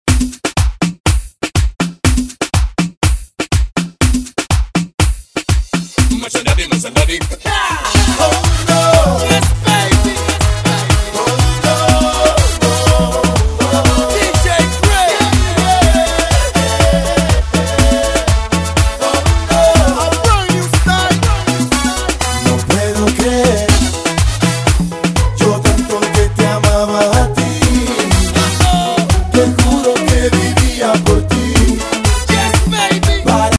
His style is unique and electrifying.
DJ